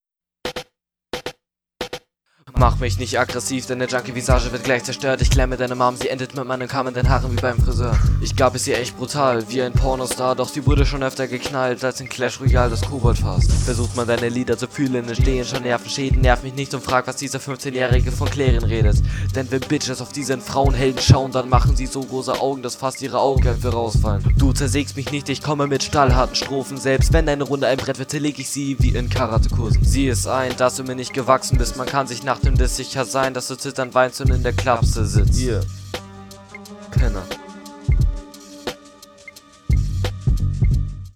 cooler style könnte aber auch so aus der RBA 2005 sein die mp3